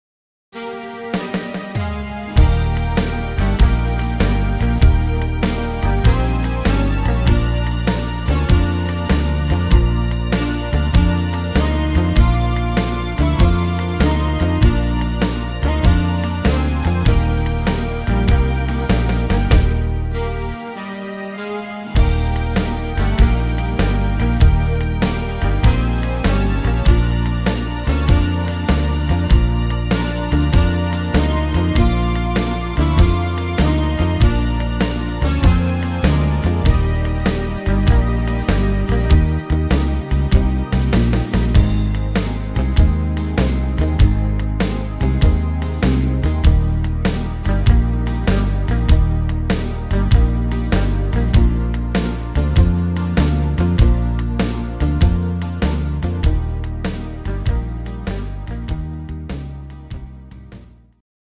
instrumental Saxophon